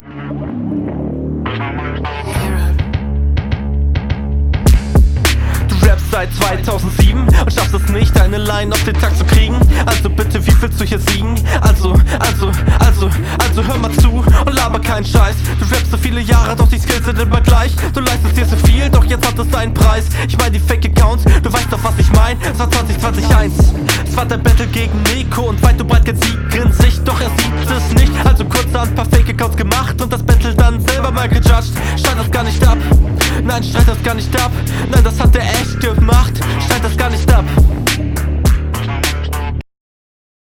Flow weak, Mix auch, Punches ok, Reime billig
Flowlich gefällt mir das ganz gut, könnte hier und da etwas runder sein aber da …
Netter Flow, wenngleich die Stimme noch ein bisschen sicherer sein könnte.
Technisch und vom Hörgenuss noch ausbaufähig aber der Flow und die Punchlines sind solide.